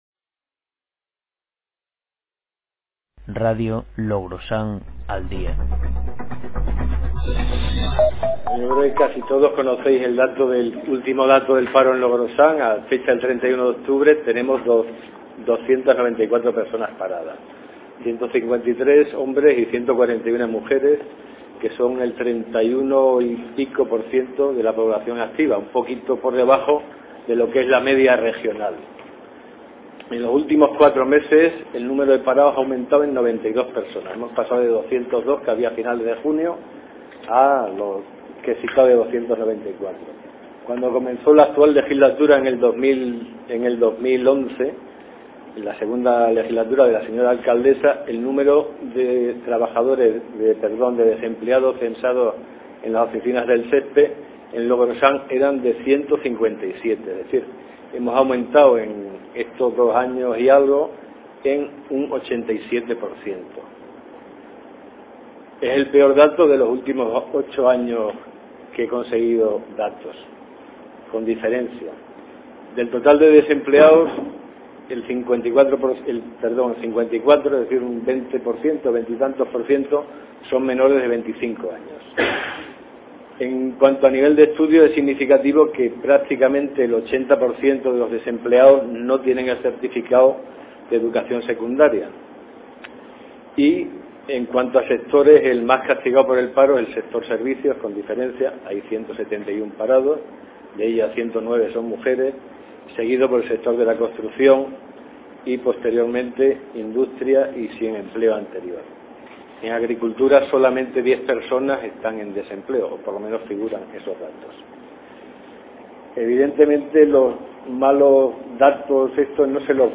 El acto estaba organizado por el Partido Socialista en la casa de Cultura y en él intervinieron Miguel Bernal, diputado regional y experto en el tema, (fue Secretario General de UGT Extremadura ) y Juan Carlos Hernández, Portavoz Municipal del PSOE de Logrosán. (con audio)
exposicic3b3n-de-juan-carlos.mp3